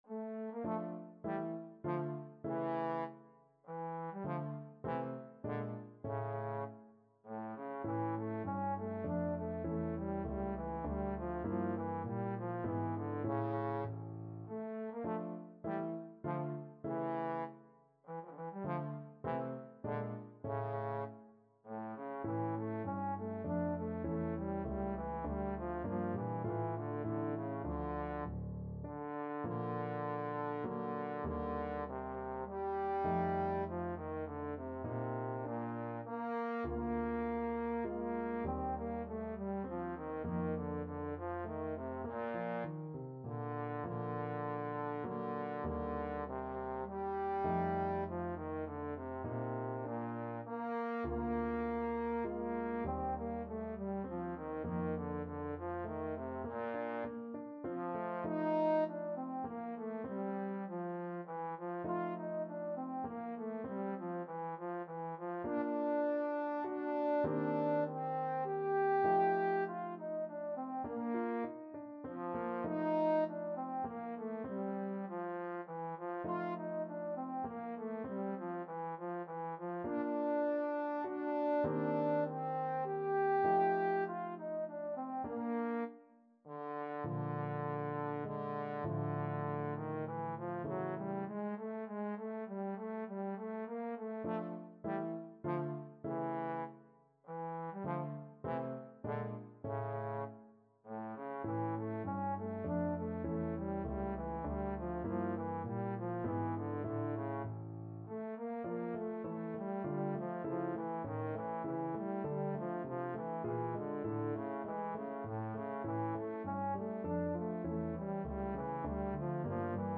Trombone version
3/4 (View more 3/4 Music)
Allegretto
Classical (View more Classical Trombone Music)